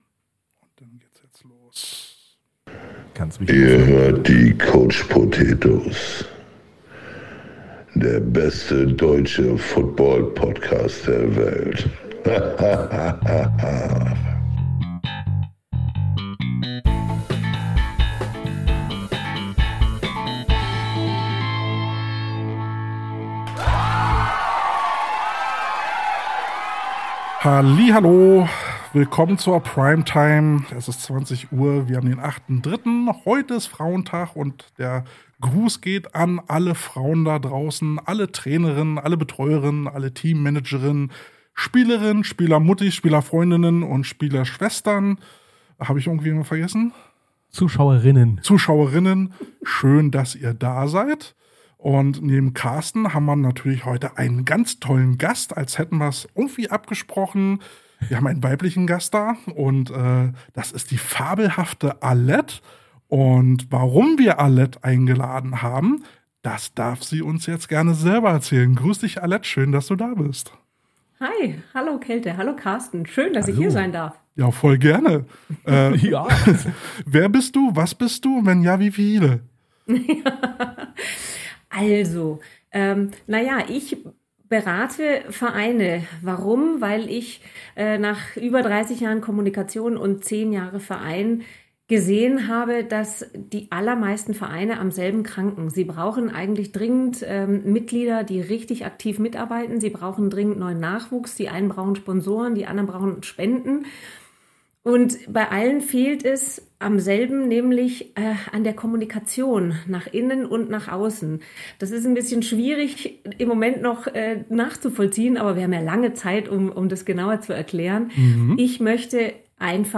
Inter view